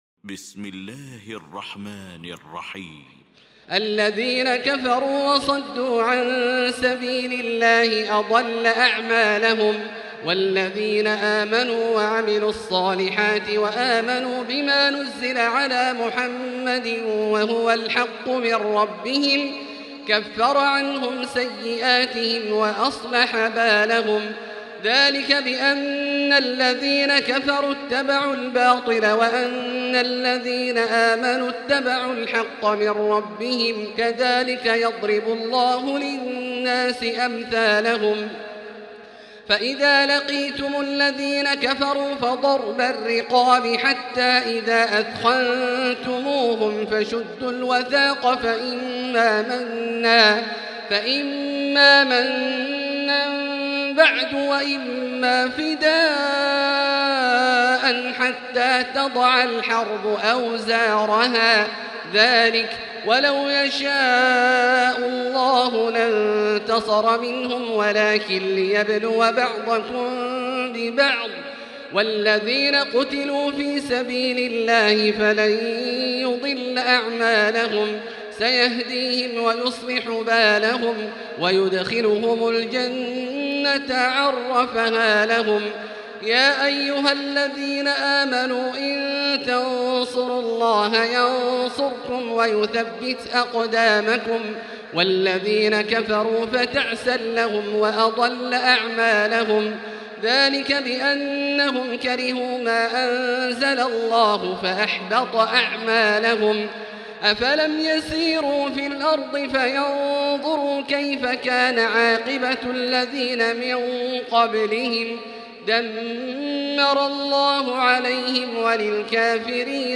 المكان: المسجد الحرام الشيخ: فضيلة الشيخ عبدالله الجهني فضيلة الشيخ عبدالله الجهني محمد